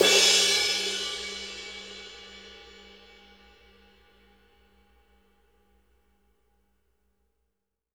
CRASH A   -L.wav